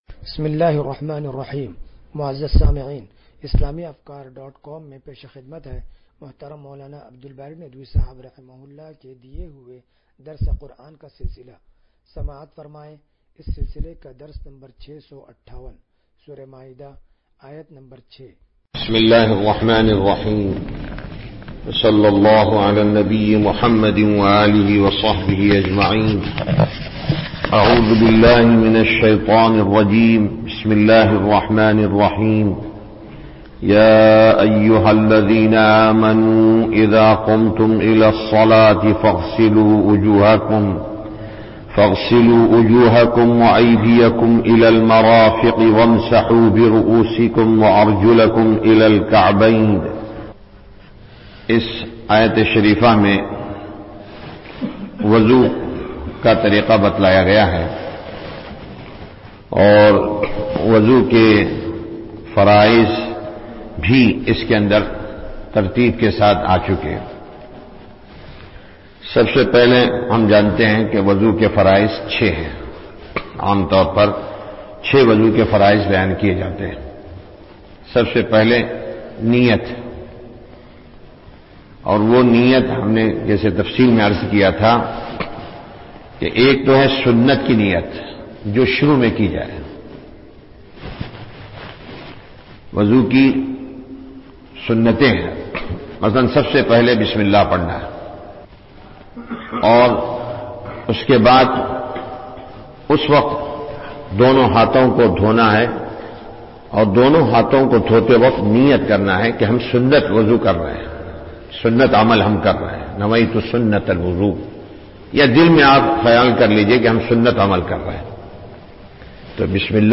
درس قرآن نمبر 0658
درس-قرآن-نمبر-0658.mp3